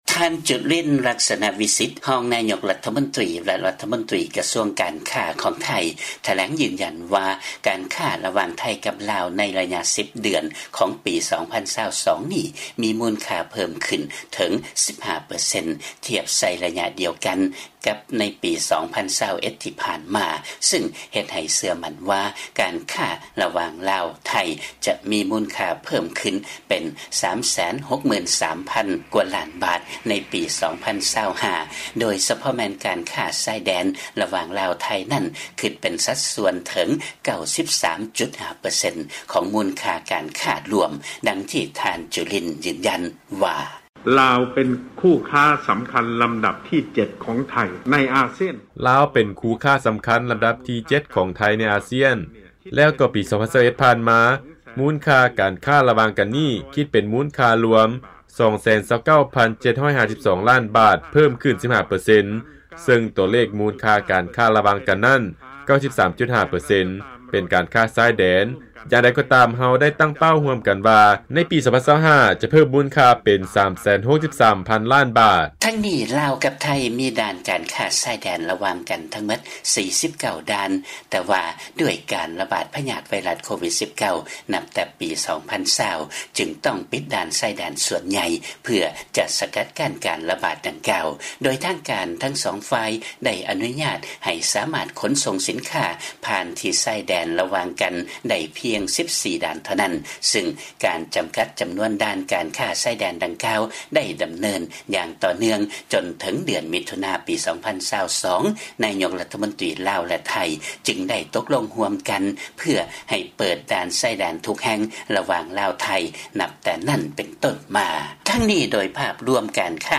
ຟັງລາຍງານ ລັດຖະມົນຕີ ໄທ ເຊື່ອວ່າມູນຄ່າການຄ້າລະຫວ່າງ ລາວກັບໄທ ເພີ່ມຂຶ້ນເປັນກວ່າ 363,000 ລ້ານບາດໃນປີ 2025.